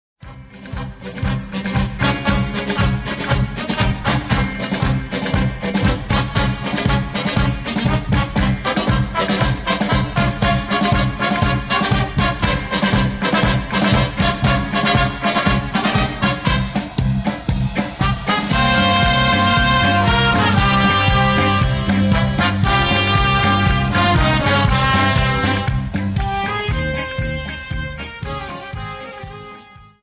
Dance: Paso Doble Song
Phrasing, 2 Highlights